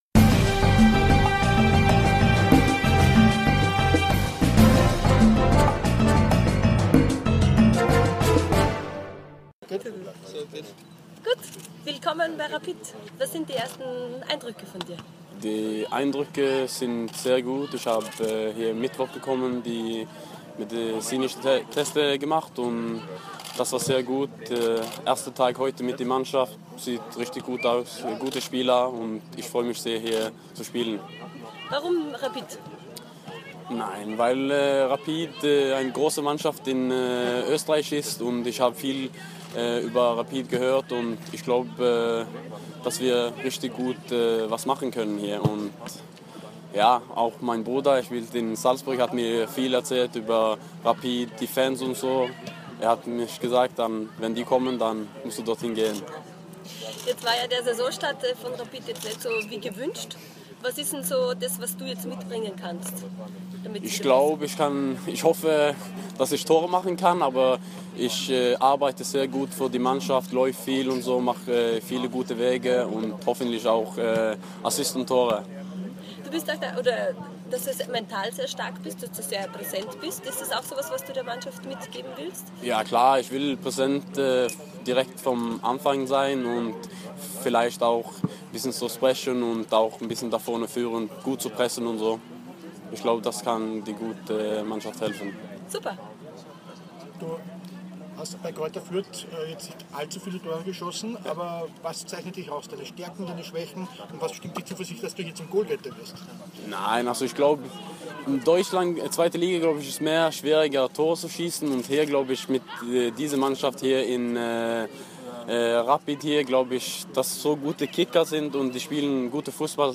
Interview mit Rapid-Neuzugang Veton Berisha